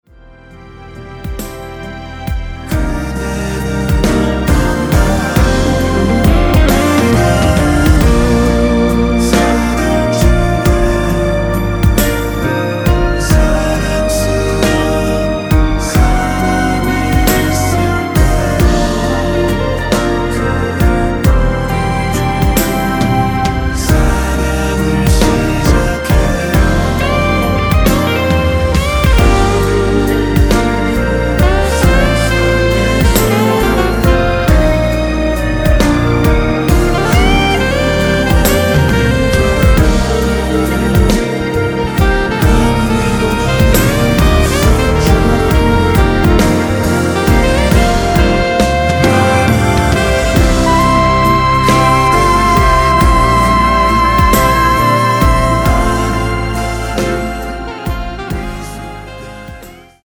순수 코러스만 들어가 있으며 멤버들끼리 주고 받는 부분은 코러스가 아니라서 없습니다.(미리듣기 확인)
원키에서(-1)내린 멜로디와 코러스 포함된 MR입니다.(미리듣기 확인)
Bb
앞부분30초, 뒷부분30초씩 편집해서 올려 드리고 있습니다.
중간에 음이 끈어지고 다시 나오는 이유는